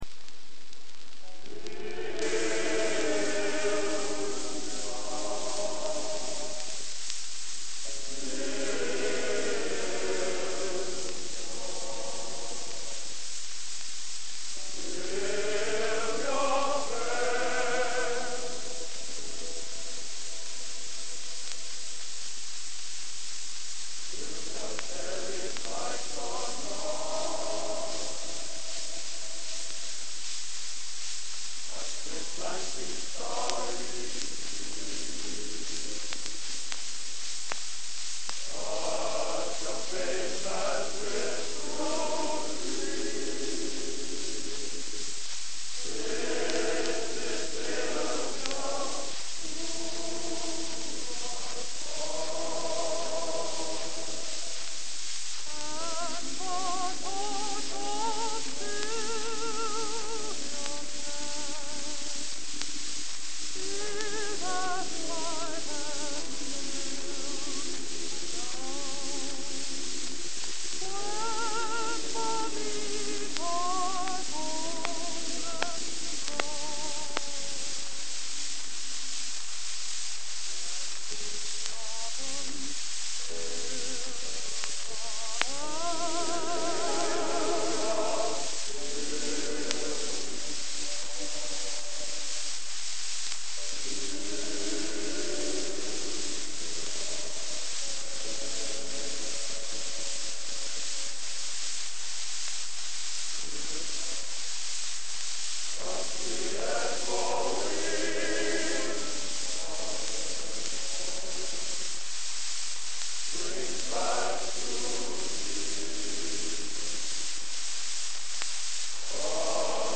Genre: | Type: Featuring Hall of Famer |Studio Recording